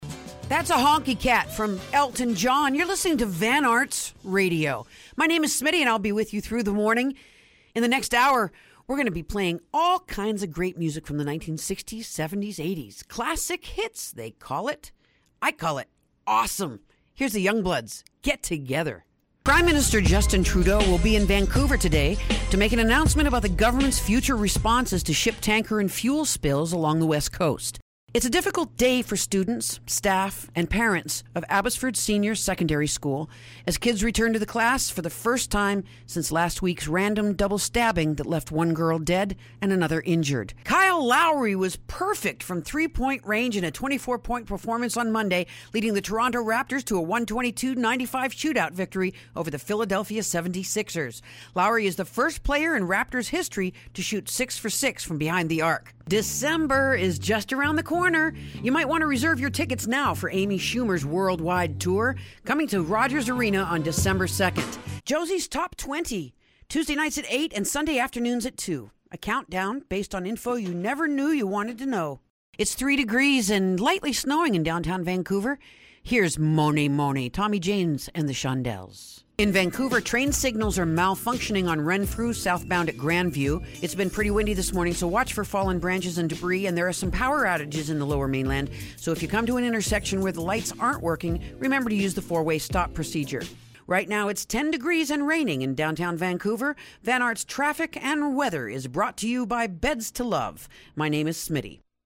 Radio Anchor Demo